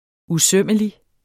Udtale [ uˈsœməli ]